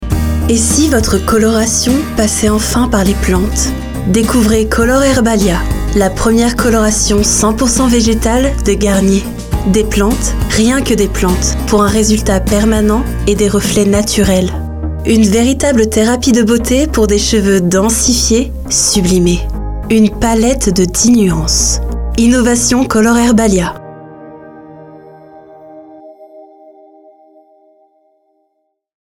Publicité, Color Herbalia
Voix off
18 - 40 ans - Contralto